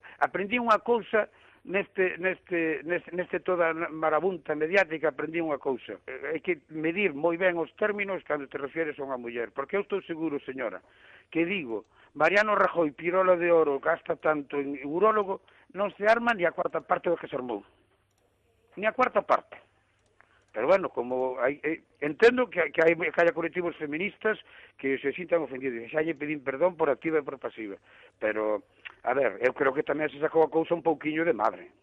El concejal del BNG de Cambados que tuvo que dimitir por llamar "chochito de oro" a Soraya Sáenz de Santamaría en su blog vuelve por sus fueros. En una entrevista en Radiovoz, esta mañana, ha dicho que "si hubiera llamado "pirola de oro" a Rajoy no se habría armado tanto lío".